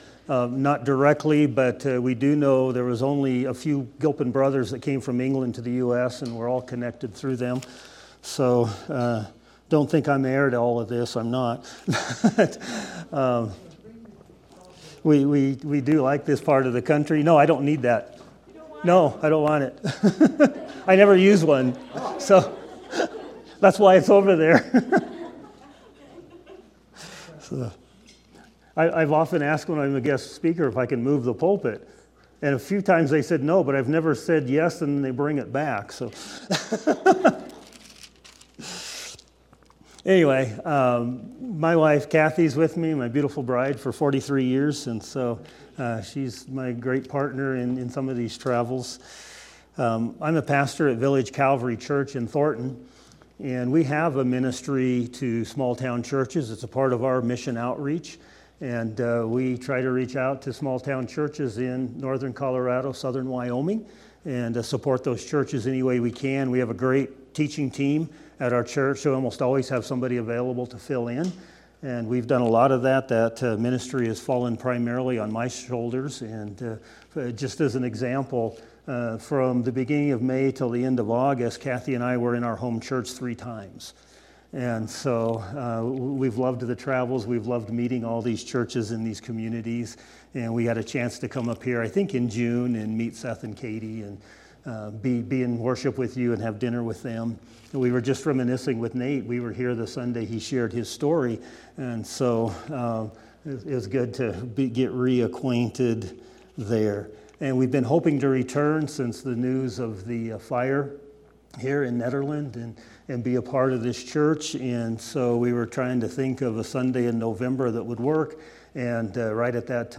November 2nd, 2025 Sermon